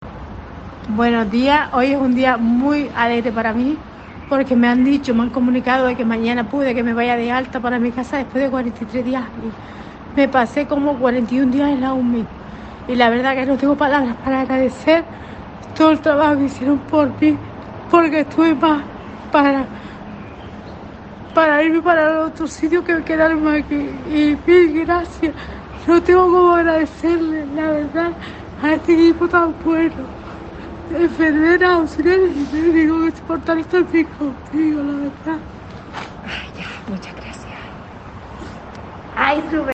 “No tengo palabras para agradecer todo el trabajo que hicieron por mí”, asegura la señora emocionada.
“Mil gracias. No tengo cómo agradecerle a este equipo tan bueno haber estado aquí conmigo cuidándome”, ha expresado emocionada.